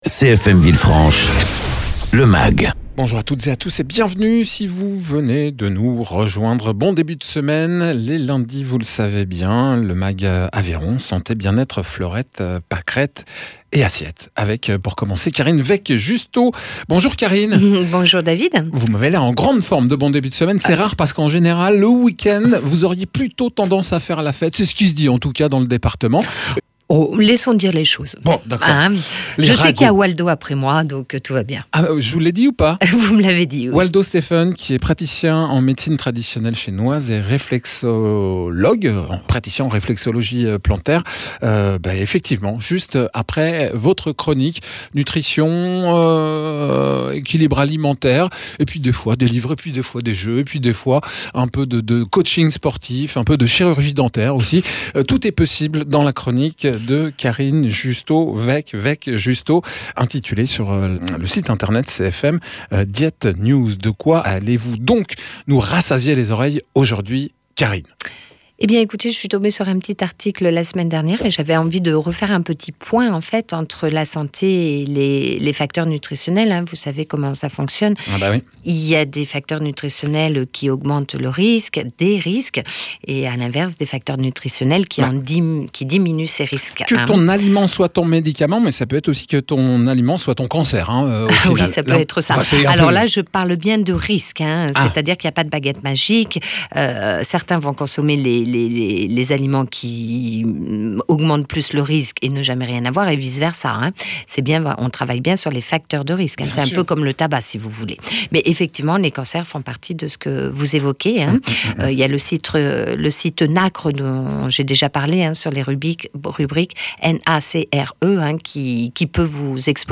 nutritionniste diététicien
praticien en réflexologie plantaire et Médecine Traditionnelle Chinoise